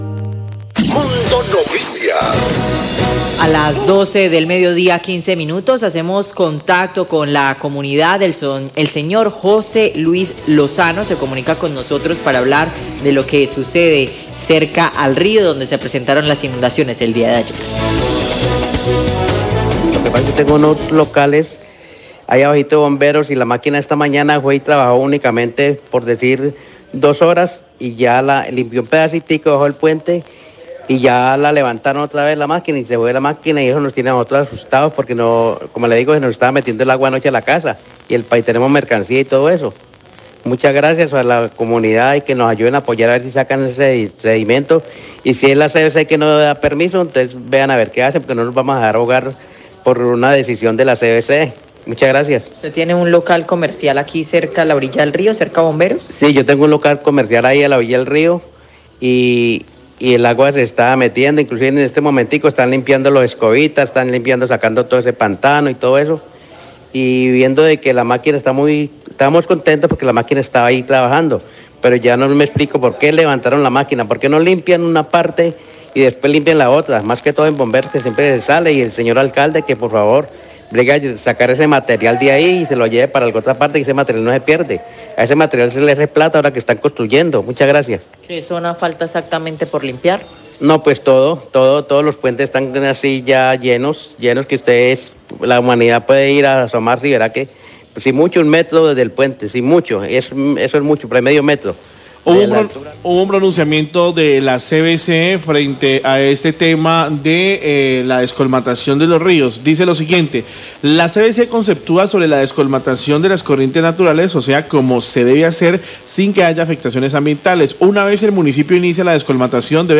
Radio
Periodista lee comunicado de la CVC donde se aclara que la CVC no otorga ni niega permisos de descolmatación del río, sólo entrega un concepto ambiental; la responsabilidad de las obras es de la alcaldía.